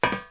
metalwalk1.mp3